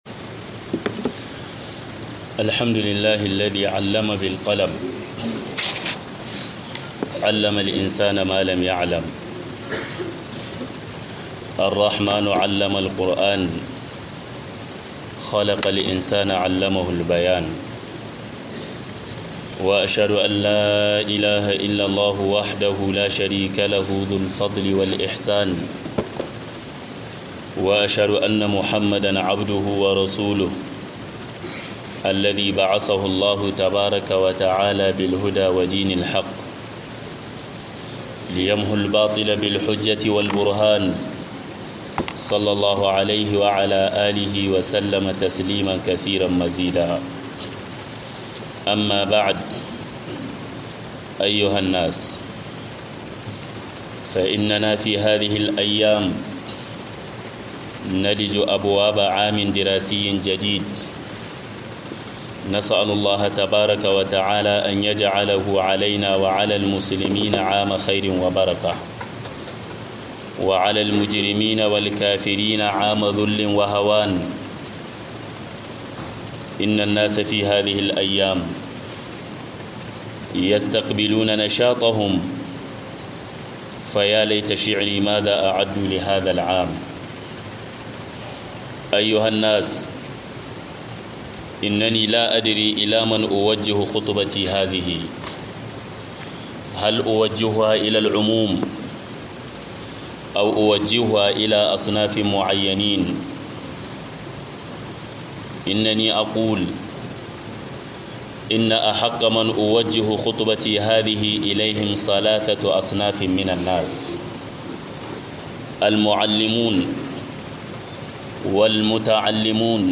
WASIYA-ZUWAGA-MALAMAI - MUHADARA